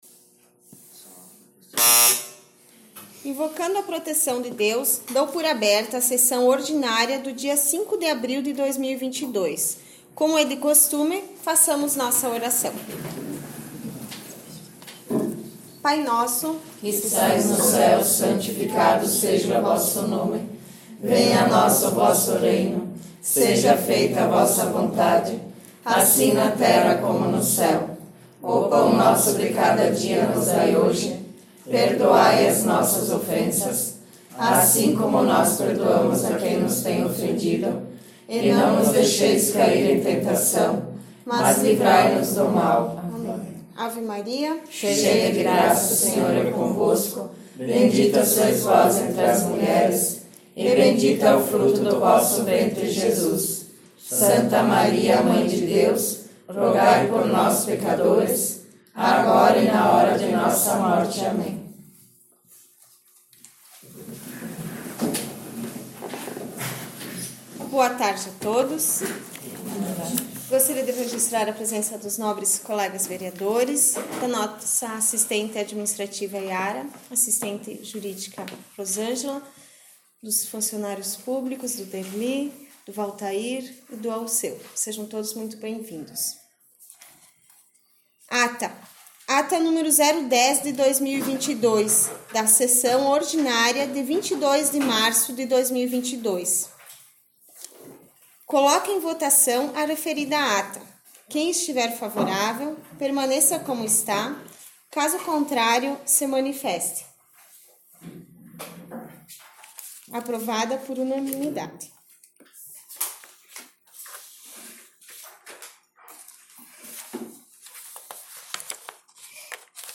8 - Sessão Ordinária 05 abril — Câmara Municipal de Boa Vista do Sul
Áudio/Gravação das Sessões da Casa Legislativa Todos os Áudios ÁUDIO SESSÕES 2021 ÁUDIO DAS SESSÕES 2020 ÁUDIO DAS SESSÕES 2019 ÁUDIO DAS SESSÕES 2022 7 - Sessão Ordinária 22 de março 8 - Sessão Ordinária 05 abril